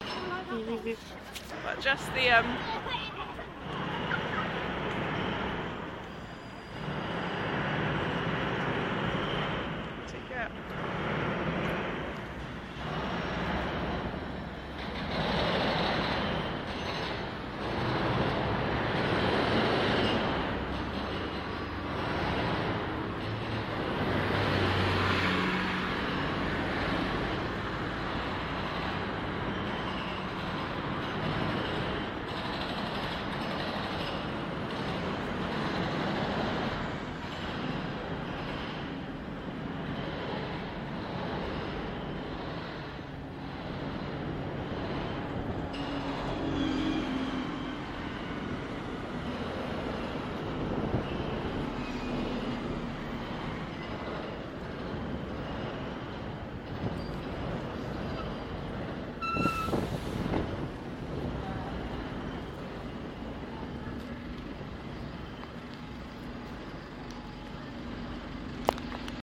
Sounds of a high street